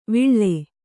♪ viḷḷe